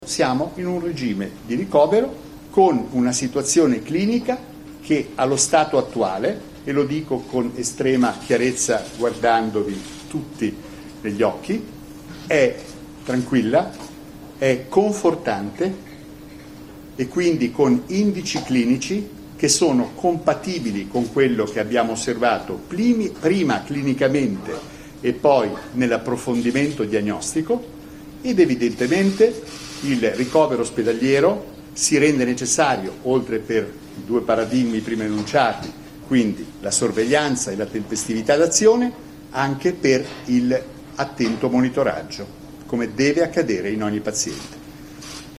Sono le parole di Alberto Zangrillo, medico personale di Berlusconi, pronunciate durante la conferenza stampa all’ospedale San Raffaele di Milano per aggiornare il bollettino medico sull’ex capo del governo.
zangrillo-h1730-berlusconi.mp3